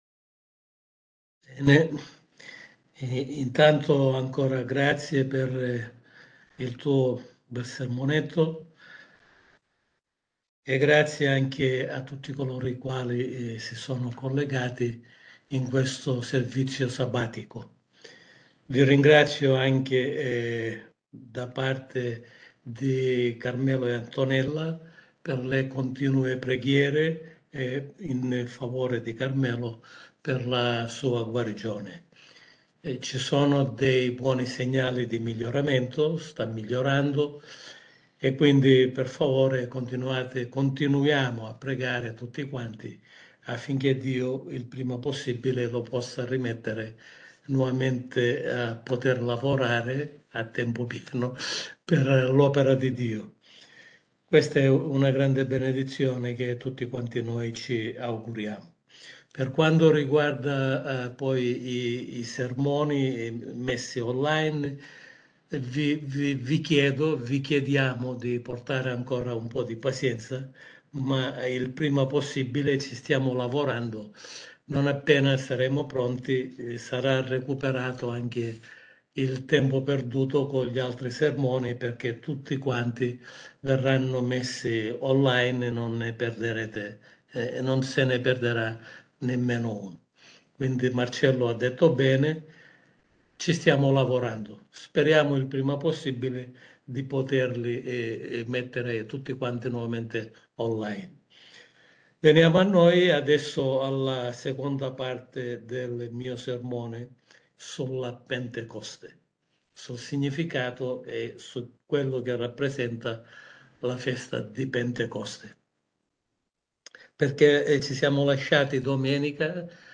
La Pentecoste parte 2 – Sermone pastorale